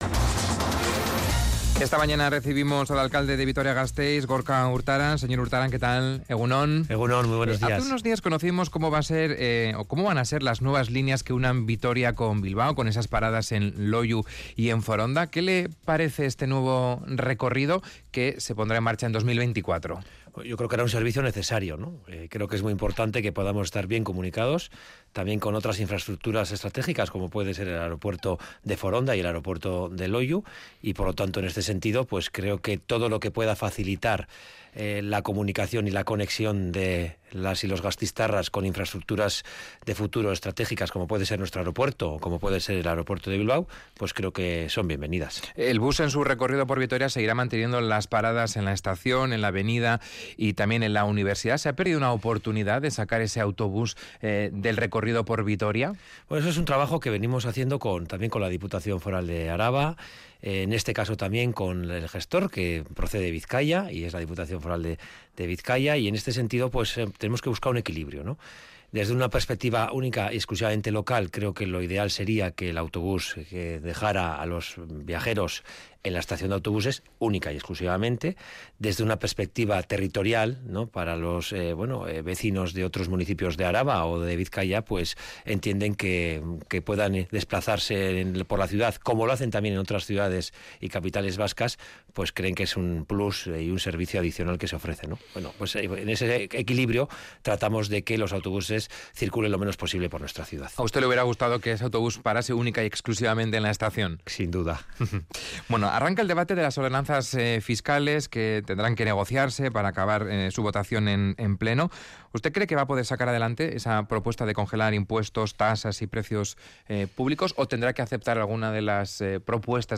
Entrevistado en Radio Vitoria, el alcalde de Vitoria-Gasteiz, Gorka Urtaran, ha adelantado las previsiones de ahorro energético que barajan gracias a las medidas implementadas